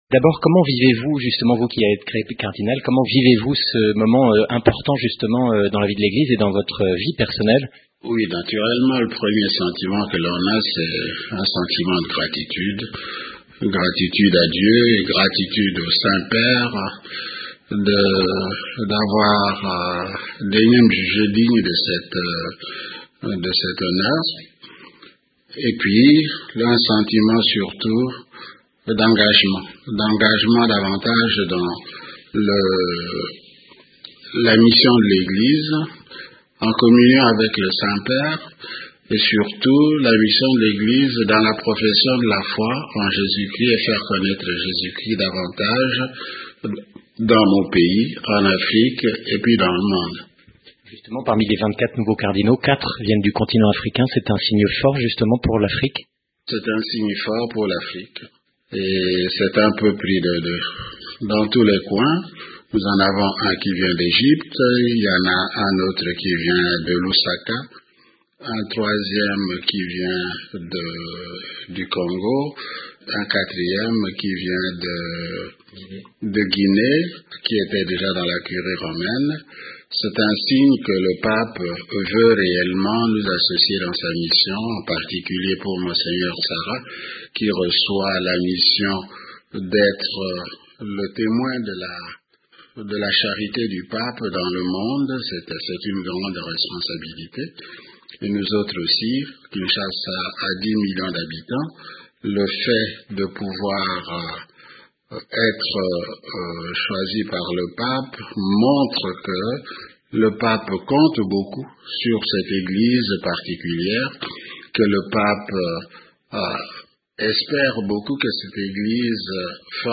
Le Cardinal Laurent Monsengwo Pasinya répond aux questions de de Radio Vatican.